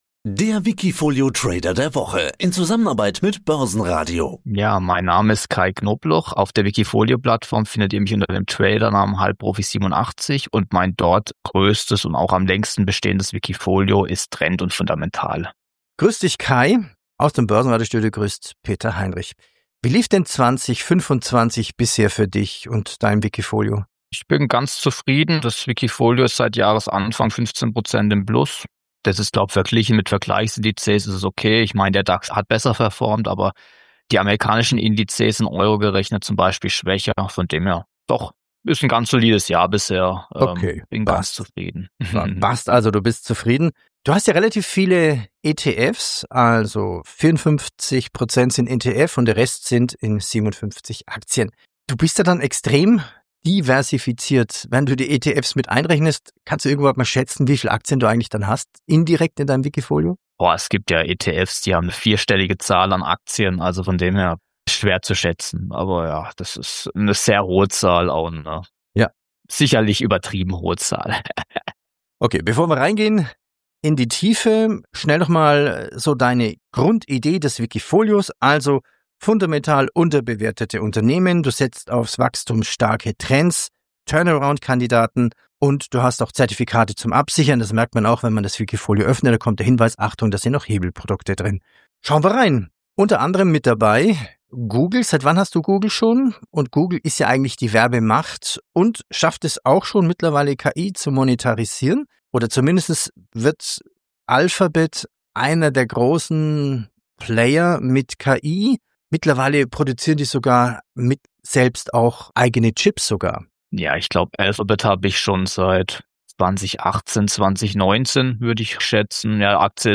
Defensive Werte stärken" ~ Die besten wikifolio-Trader im Börsenradio Interview Podcast